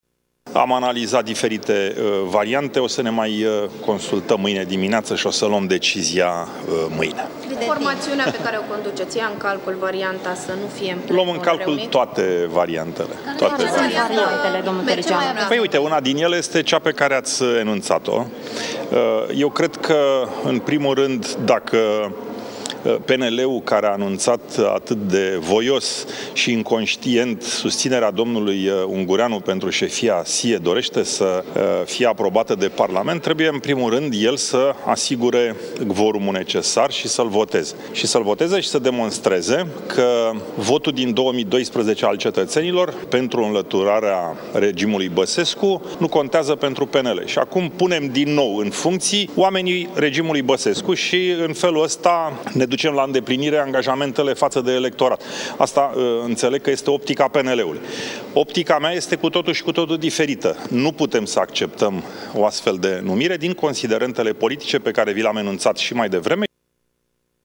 După o ședință a reprezentanților Coaliției de guvernare, Liviu Dragnea a declarat că decizia privind boicotul PSD nu a fost luată, dar este o posibilitate. O declarație asemănătoare a făcut și președintele Senatului și al PLR, Călin Popescu Tăriceanu: